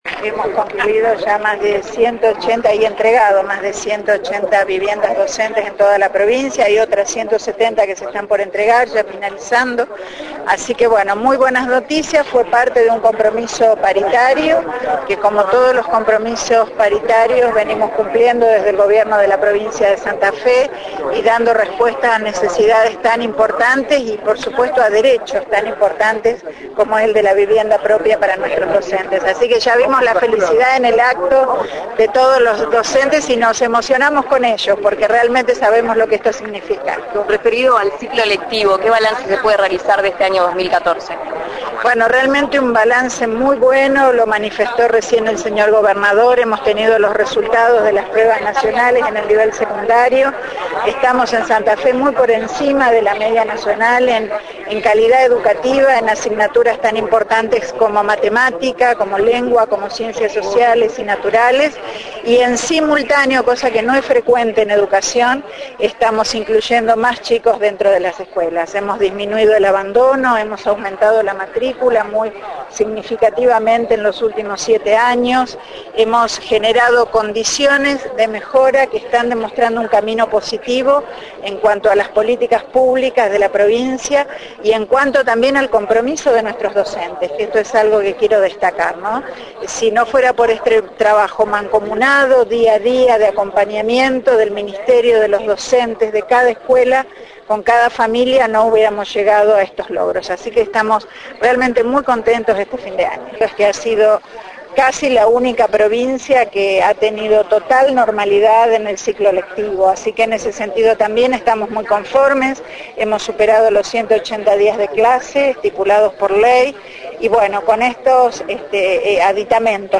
Declaraciones de Balagué: “Ya entregamos más de 180 viviendas docentes en toda la provincia”.